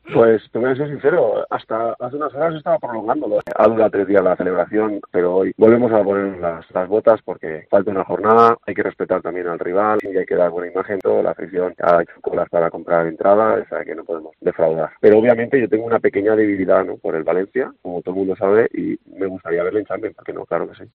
ENTREVISTA MÍCHEL